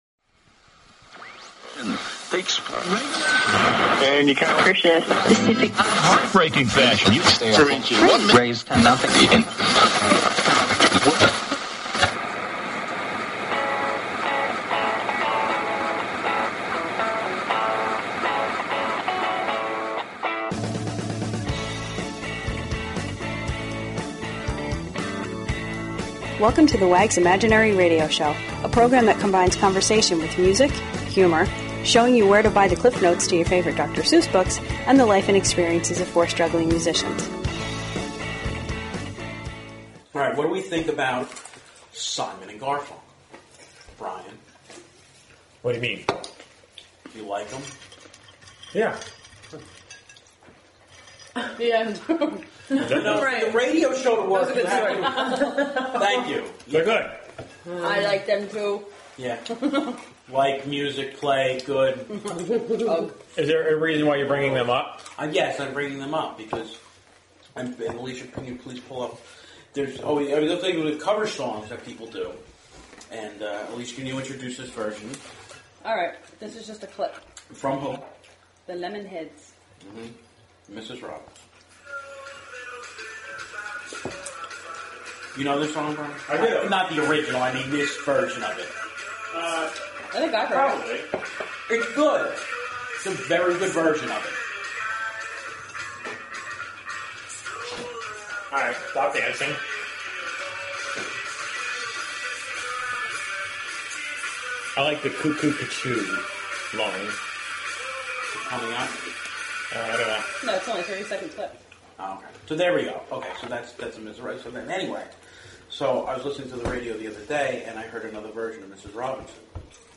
Talk Show Episode, Audio Podcast, The_Wags_Imaginary_Radio_Show and Courtesy of BBS Radio on , show guests , about , categorized as
It is a podcast that combines conversation with music, humor & the life experiences of four struggling musicians from New Jersey.